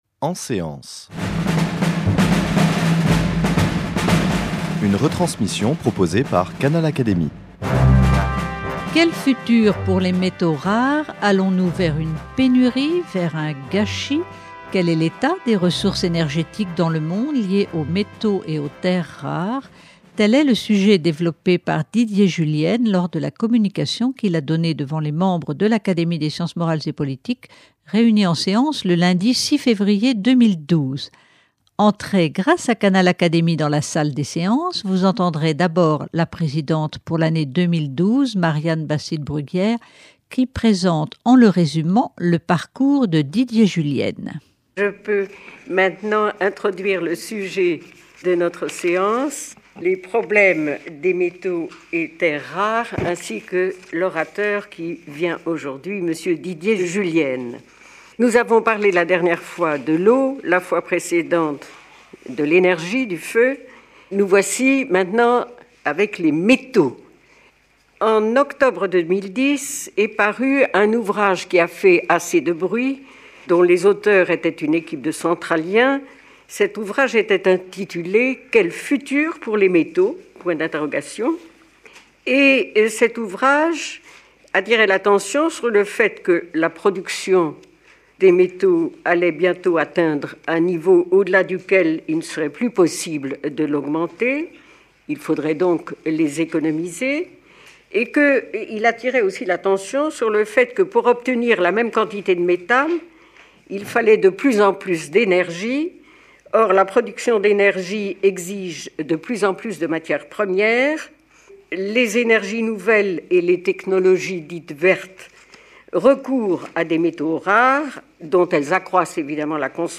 devant les académiciens réunis en séance.